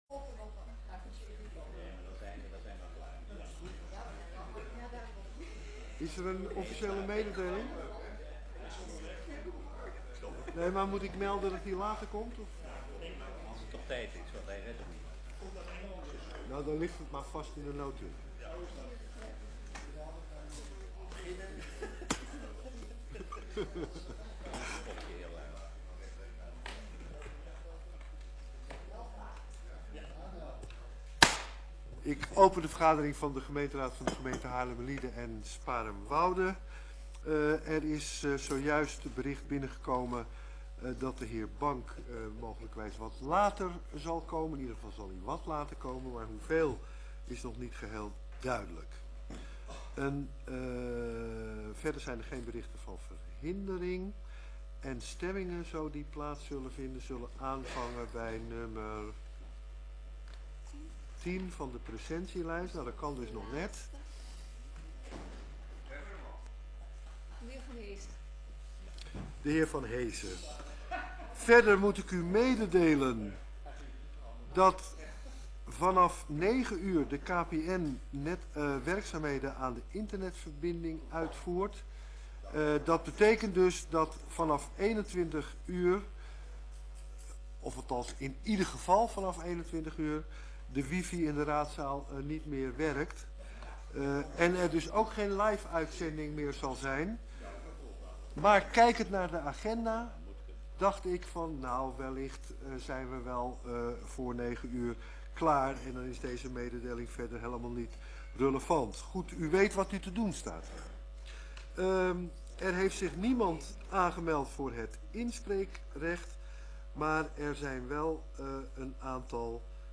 Gemeenteraad 27 november 2012 20:00:00, Gemeente Haarlemmermliede
Locatie: Raadzaal